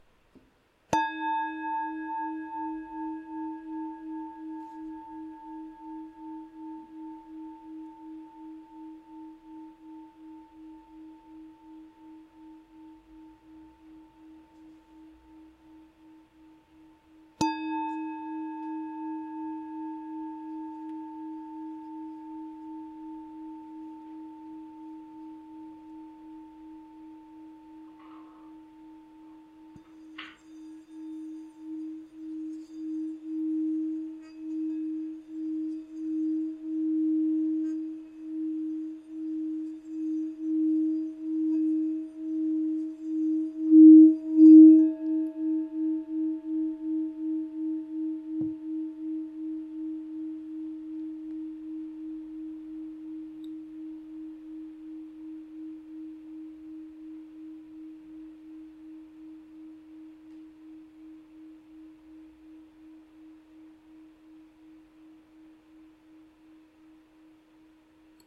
Nota Armonica LA(A) 5 896 HZ
Nota di fondo RE d4 318 HZ
Campana Tibetana Nota RE d4 318 HZ